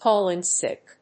cáll ín síck